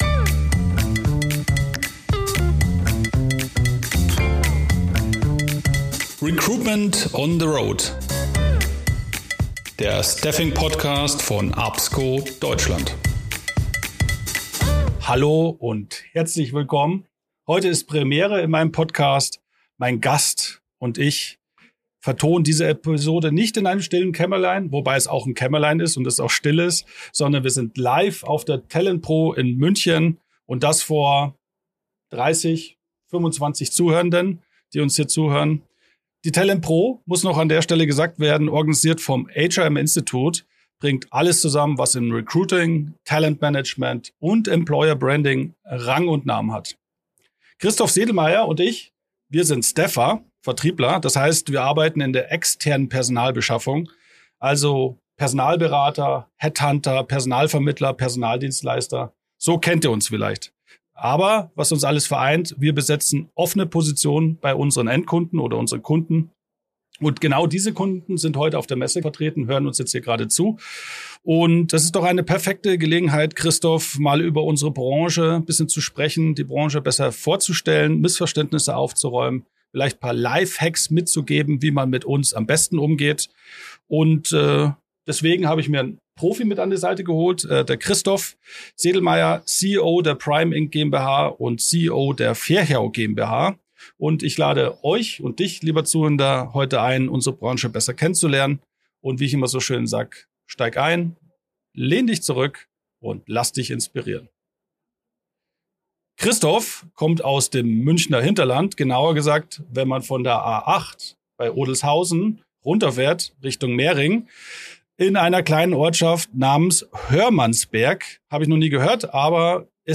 Diese Episode ist eine Premiere: Zum ersten Mal nehmen wir „Recruitment on the Road“ live auf – mit Publikum, auf der Bühne der TALENTpro in München!